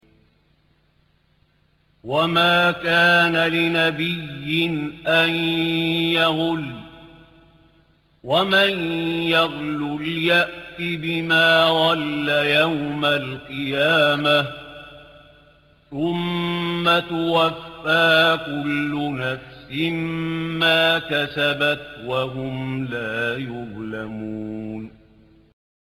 1- رواية حفص
استمع للشيخ علي الحذيفي من هنا